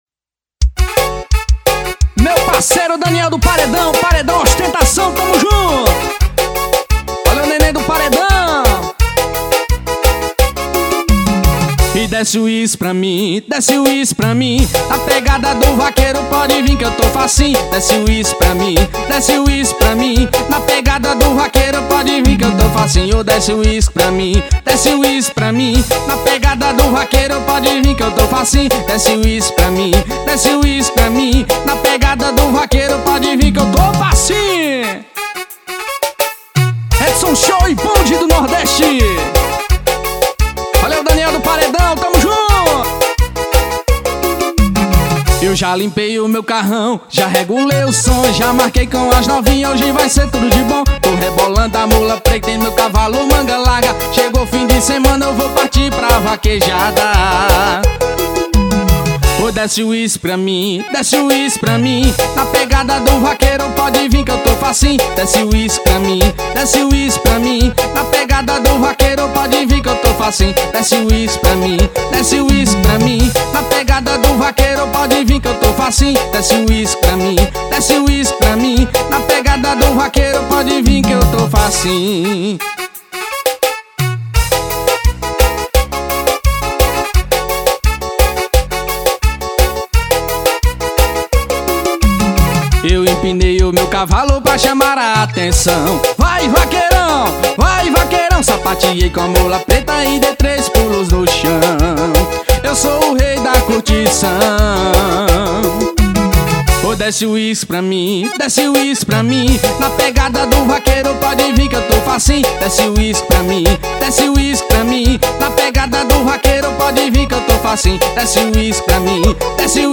AO VIVO.